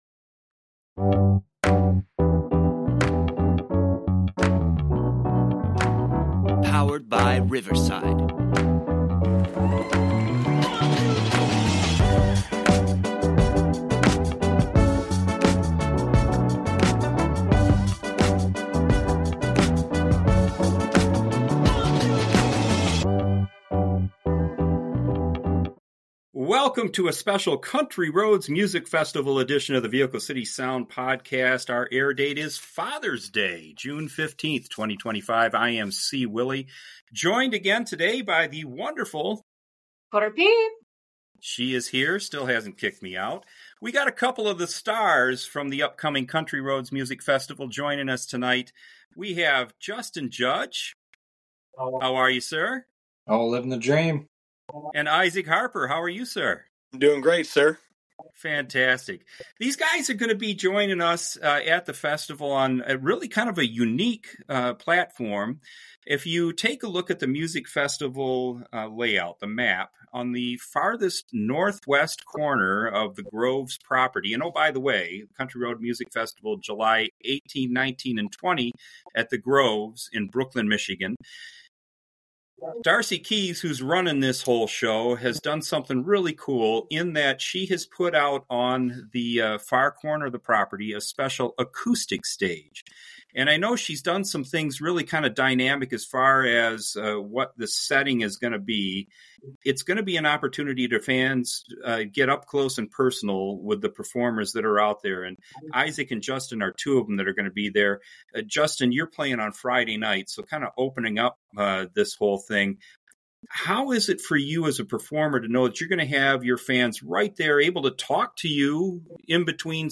In this special episode of the Vehicle City Sound podcast, we talk with two of the stars of the Country Roads Music Festival about their opportunity to rock the acoustic stage at the upcoming event!&nbsp